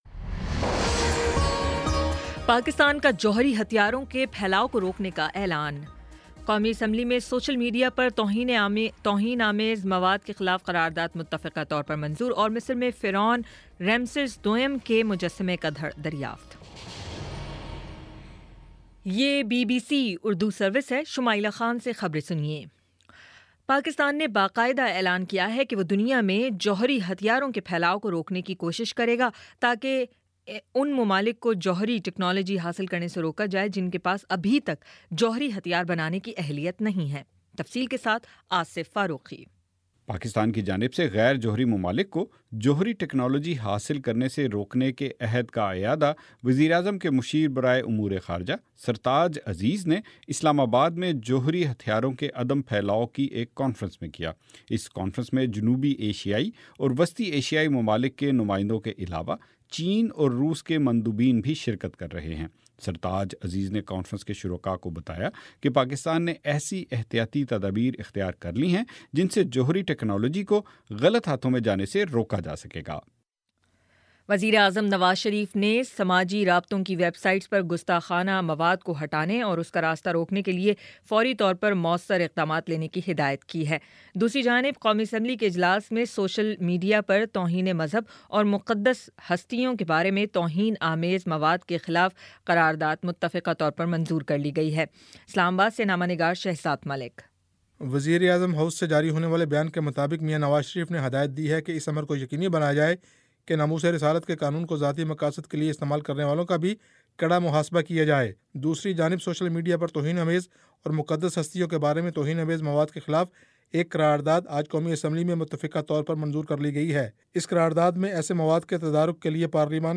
مارچ 14 : شام سات بجے کا نیوز بُلیٹن